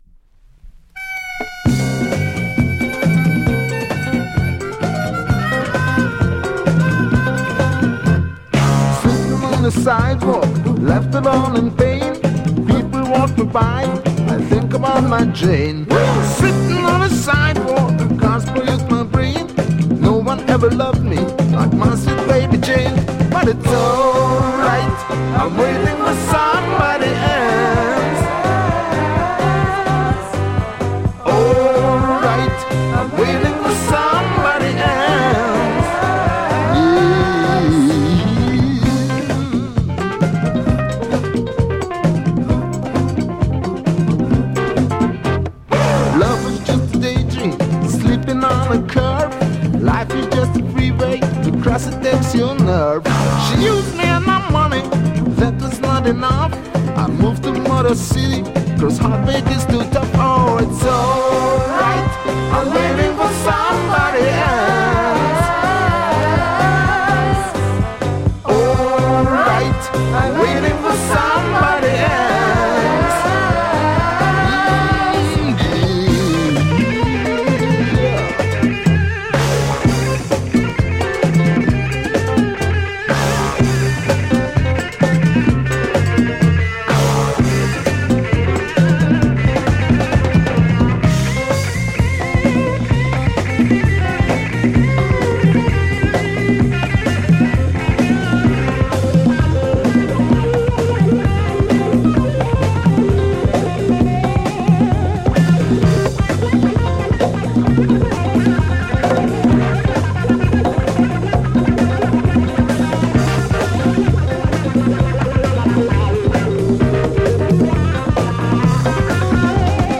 ハンガリーを代表するロックグループ